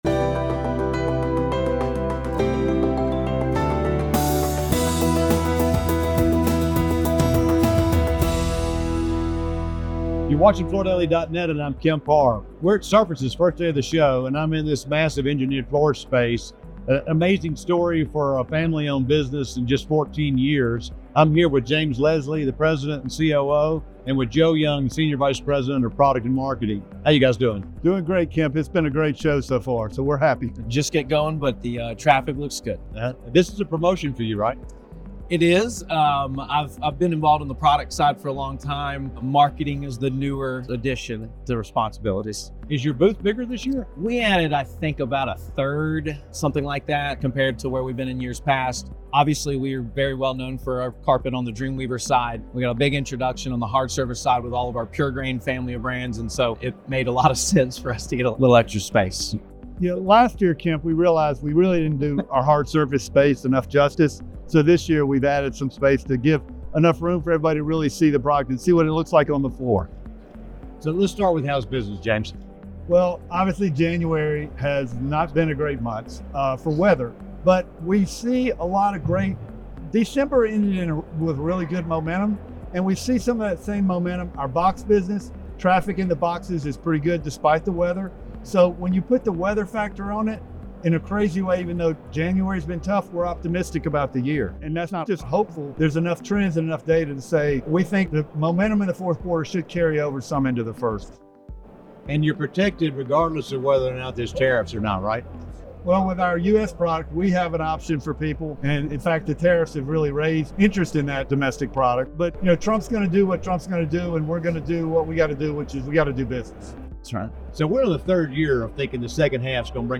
Watch the video, taped at Surfaces, for more details.